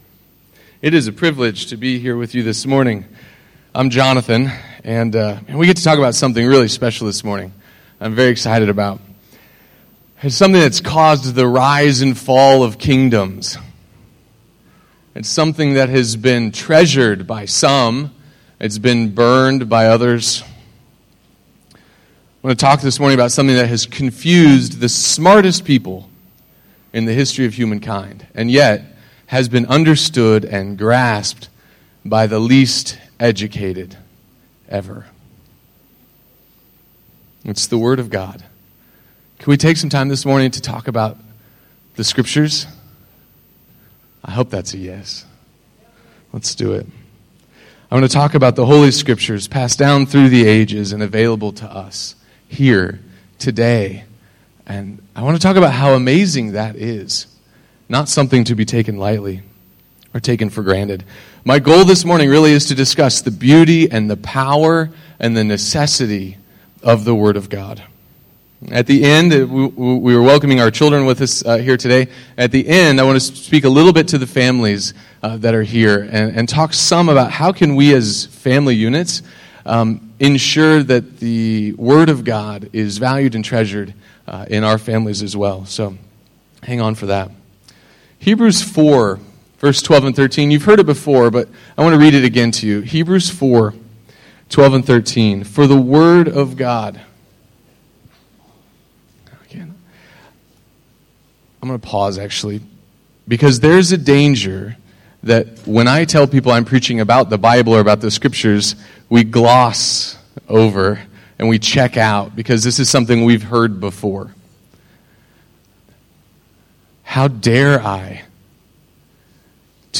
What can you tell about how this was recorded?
When Missionaries Starve — A message on the Power, Beauty, and absolute Necessity of the Word of God. Recorded at ICA, Phnom Penh Cambodia, July 2017.